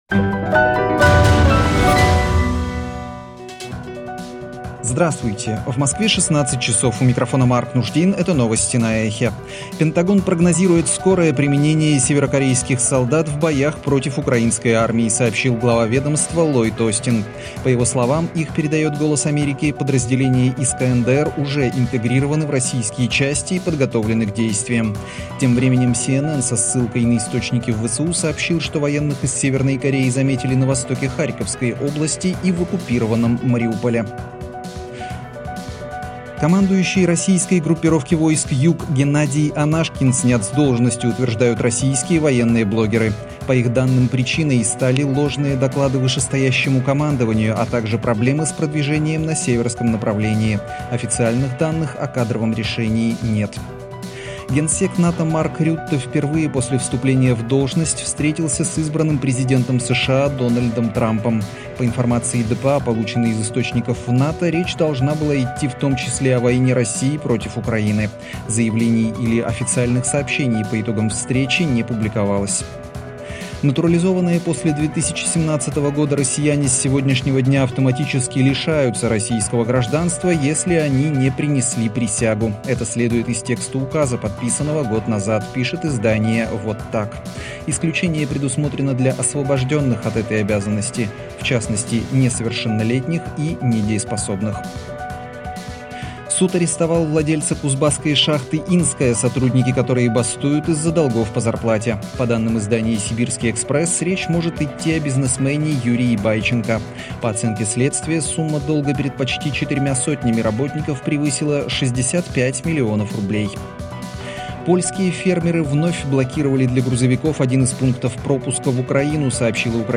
Свежий выпуск новостей